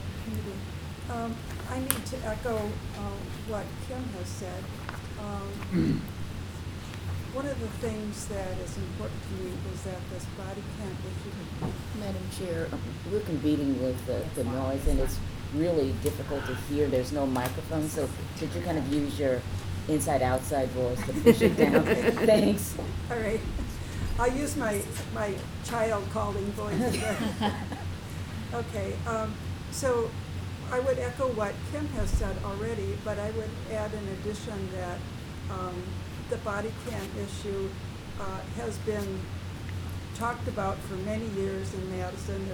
Example 2 – PSRC Room 204, Madison Municipal Building
I have multiple complaints about the room, but the biggest was that the committee members literally had to interrupt and ask people to speak up – to “use your inside outside voice” so we could hear them.  We had to interrupt speakers, staff and other members of the committee multiple times to remind them.
psrc-milw-cops-ad-hoc-noise-example.wav